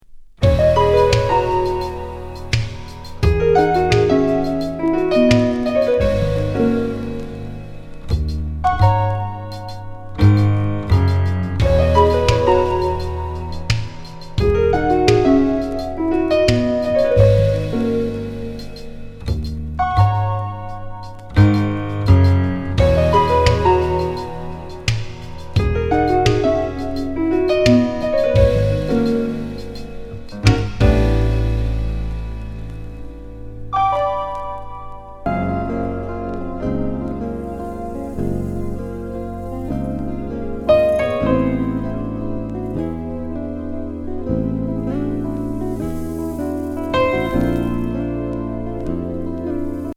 ドリーミー・メロウ・イージー